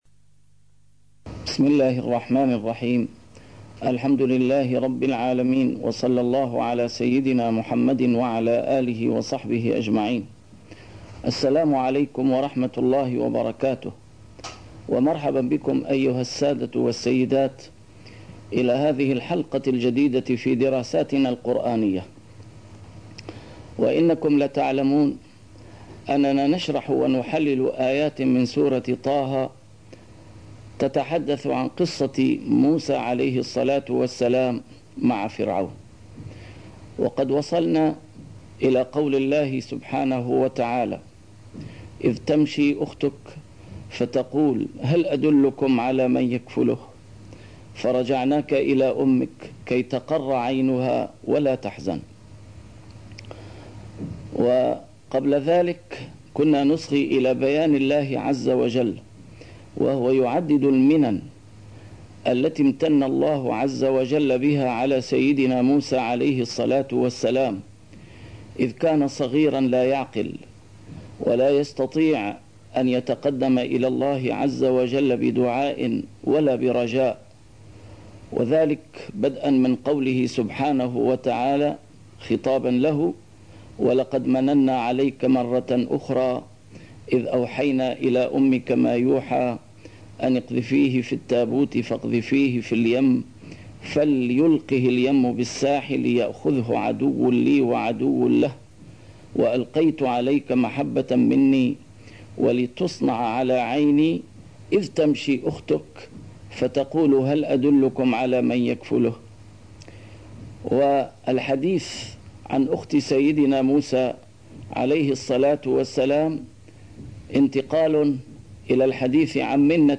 A MARTYR SCHOLAR: IMAM MUHAMMAD SAEED RAMADAN AL-BOUTI - الدروس العلمية - دراسات قرآنية - طه (قصة موسى الآية 40) + طه (قصة موسى الآية 41)